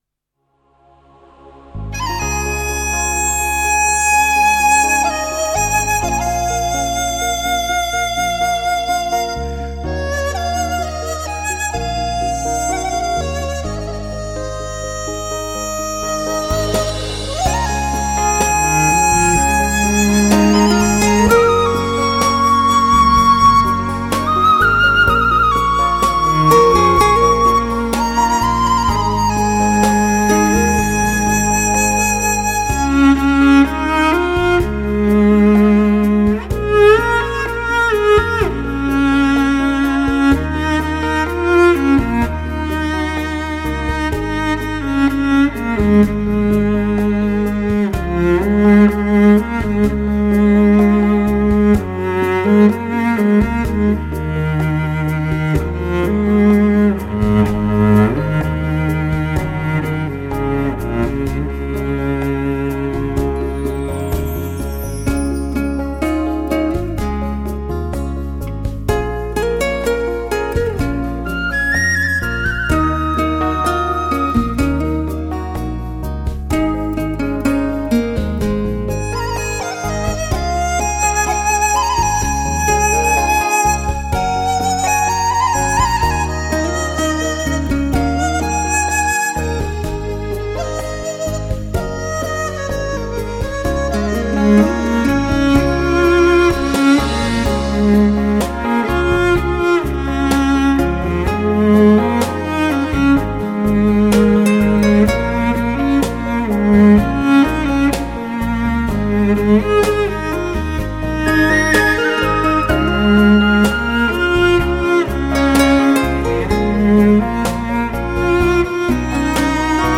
大提琴 它的音乐 时而华丽 时而朦胧 时而低沉 时而富有歌唱性
具有人声般的感染力 音乐抒情 娓娓道来
大提琴醇厚绵长的音色，瑰丽多姿的弓弦音乐表现将草原音乐那种悠远、朦胧、抒情，甚至是苍凉的意境和氛围刻画的淋漓尽致。